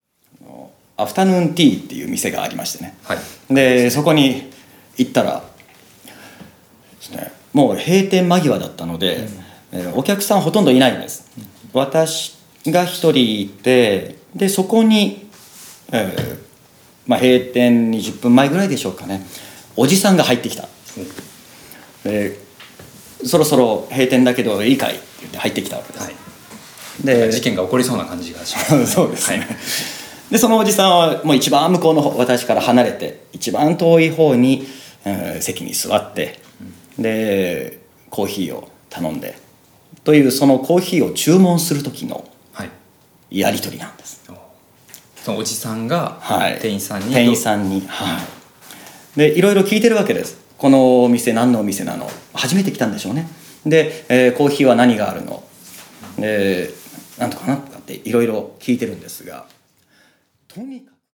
それでは、音声講座でお会いしましょう。